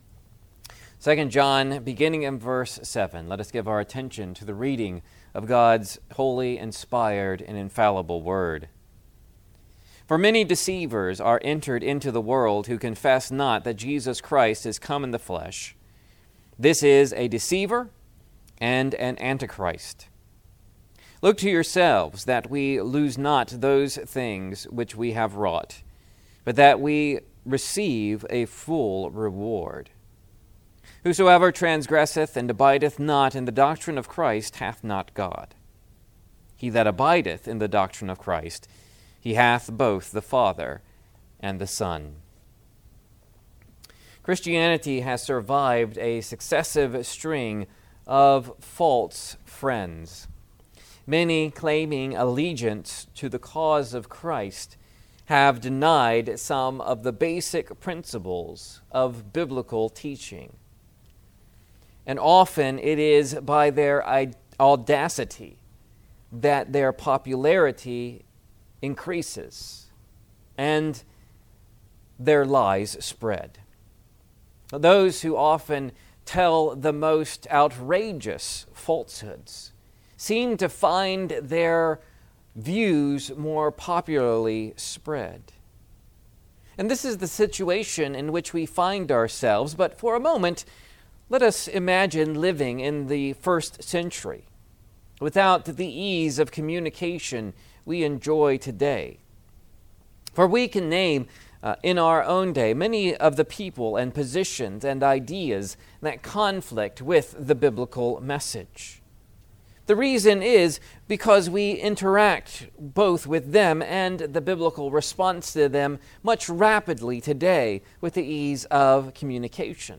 Bulletin Sermon Outline I. A Principle of Deception